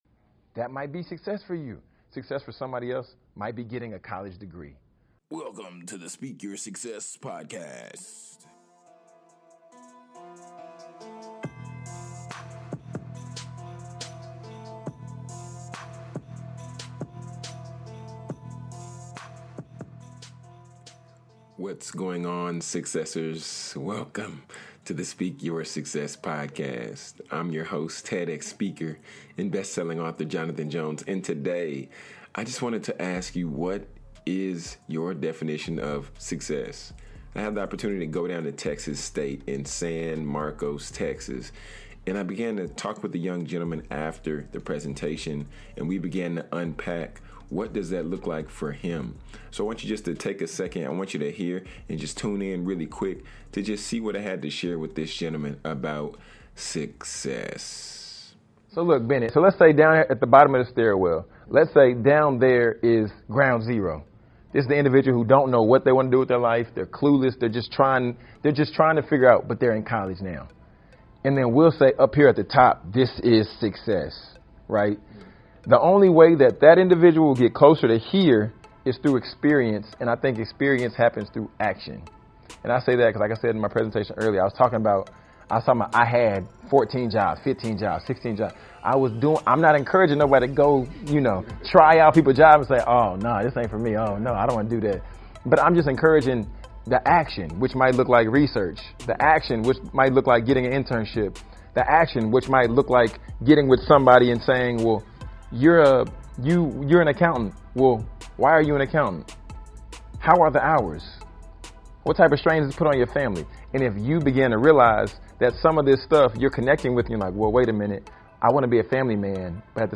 Having conversations with students after my speaking engagements are often times the best part of the presentation. Take a listen as I share a brief excerpt with this gentleman about success and how to get there.